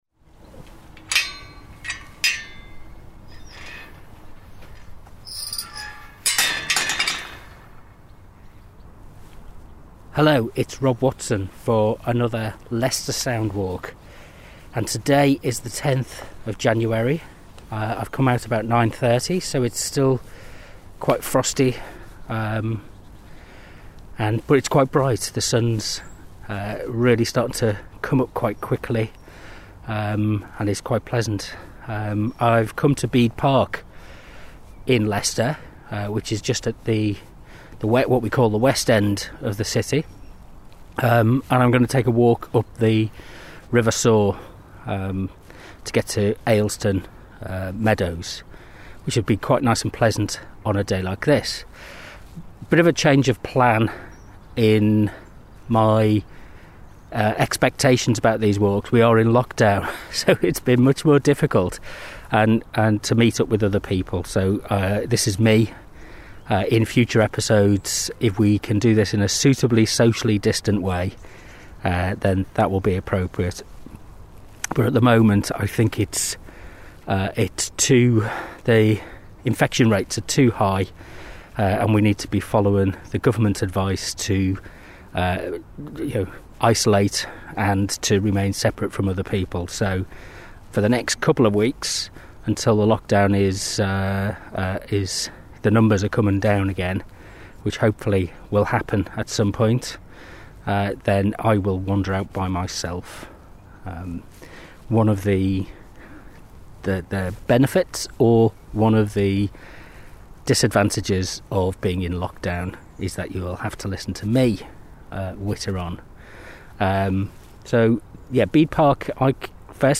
Distraction Therapy Podcast 010 – Leicester Sound Walk
Distraction-Therapy-Podcast-010-Leicester-Soundwalk.mp3